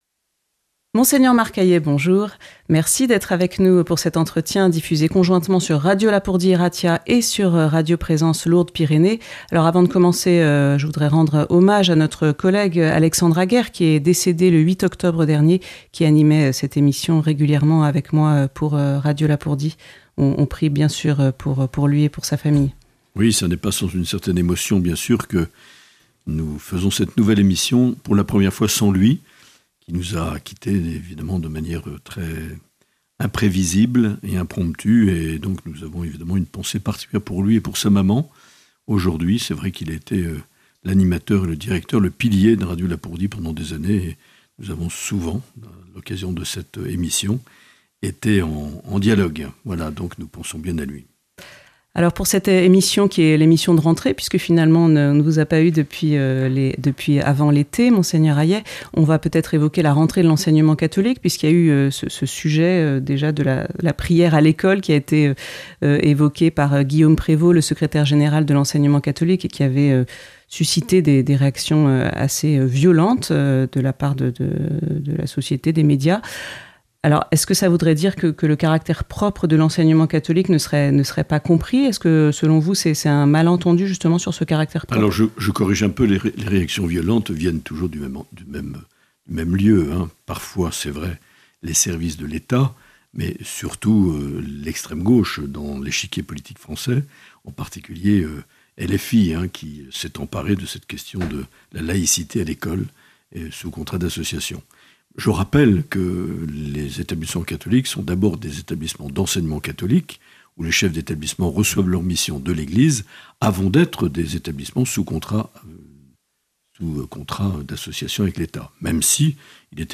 Entretien avec Mgr Marc Aillet - octobre 2025